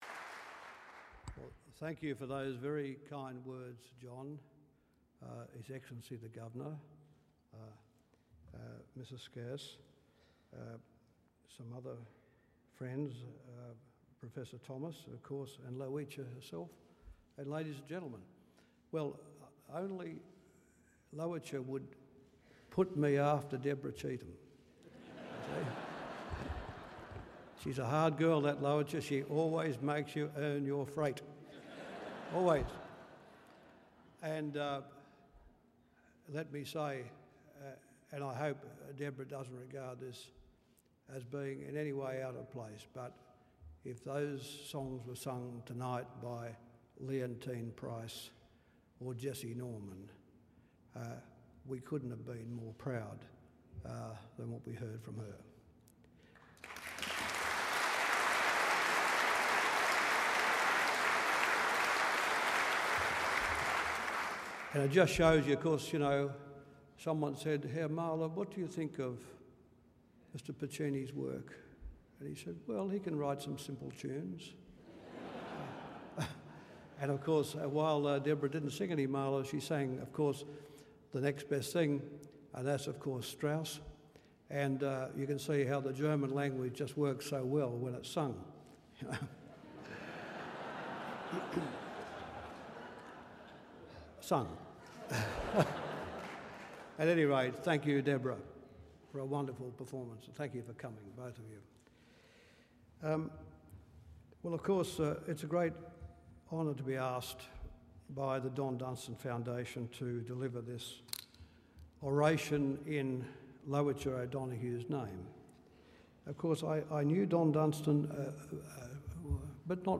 31 May 2011 Aboriginal Empowerment , Audio , Lowitja O’Donoghue Oration The Hon Paul Keating, Speech, Lowitja O’Donoghue Oration, 2011 Listen to Paul Keating’s speech at the 5th Annual Lowitja O’Donoghue Oration 2011 on Lowitja O’Donoghue and Native Title: Leadership Pointing the Way to Identity, Inclusion and Justice.